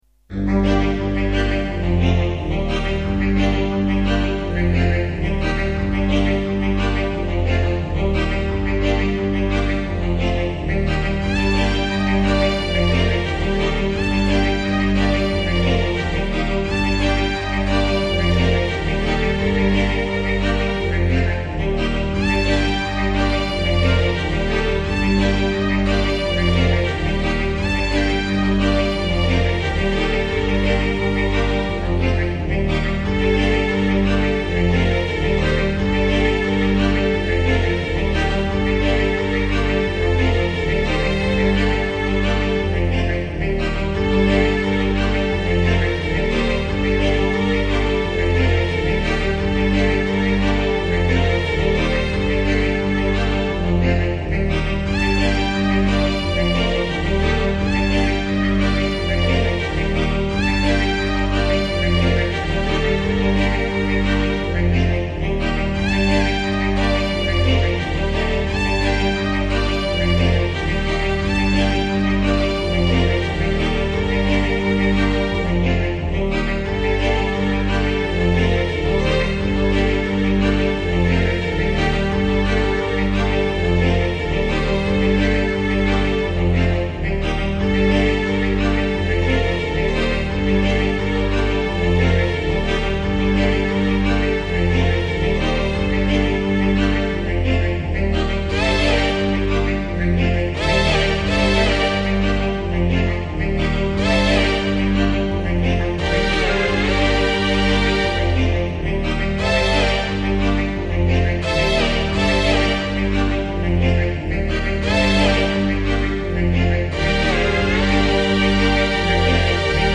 Sommige nummers uit een grijs en ver verleden, dus de geluidskwaliteit is af en toe wat minder.